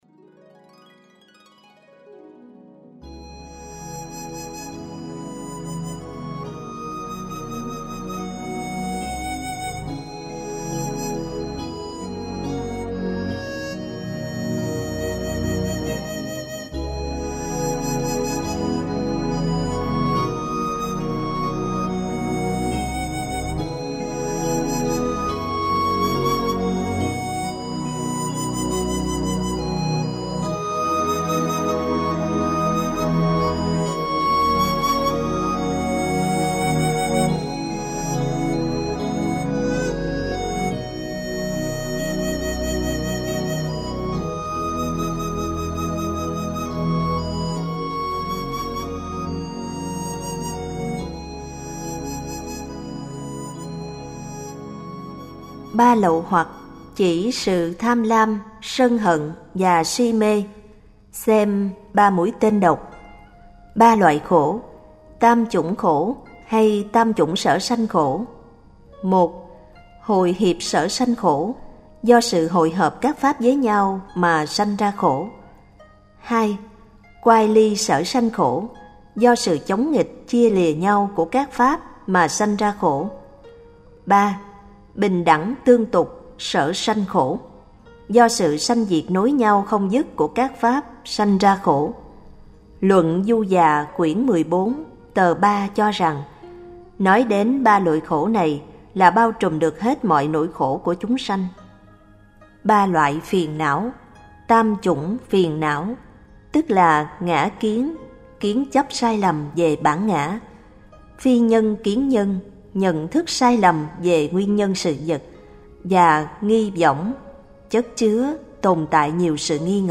Diễn đọc Kinh Đại Bát Niết-bàn 045